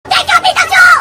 explode3.ogg